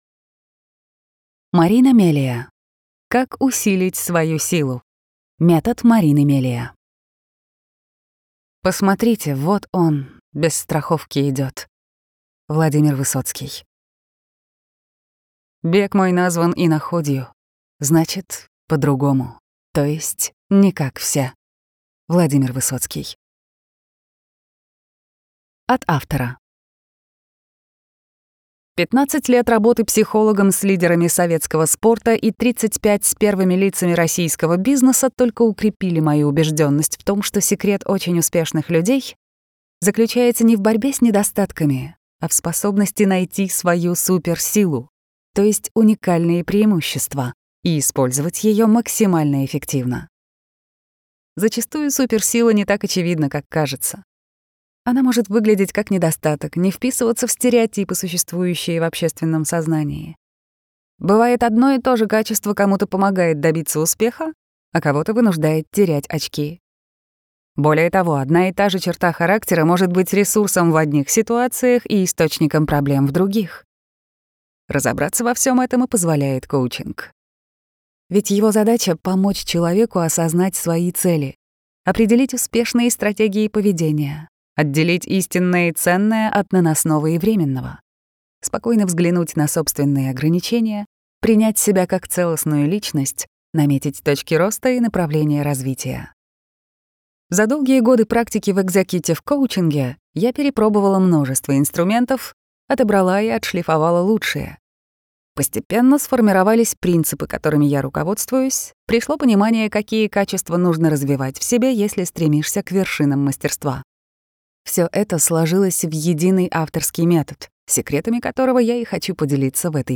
Аудиокнига Метод Марины Мелия. Как усилить свою силу | Библиотека аудиокниг